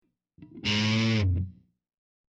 guitar hit 2 2 sec. mono 25k
guitarhit2.mp3